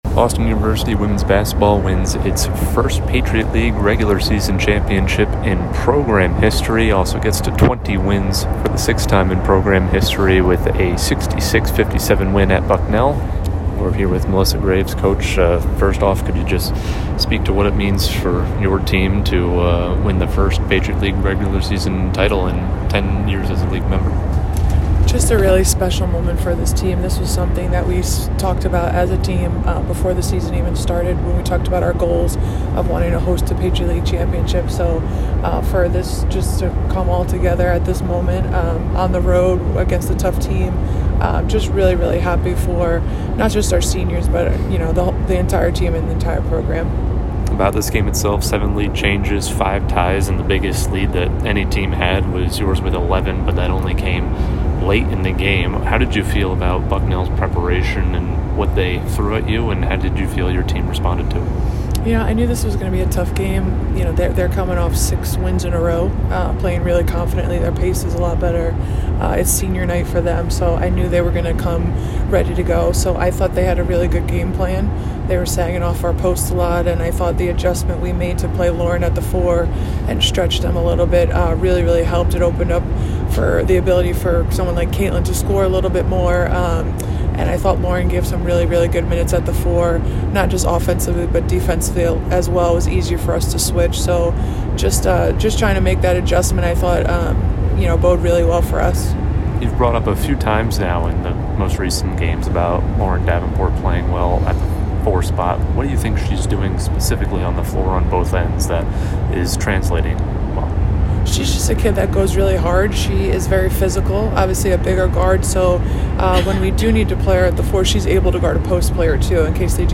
WBB_Bucknell_2_Postgame.mp3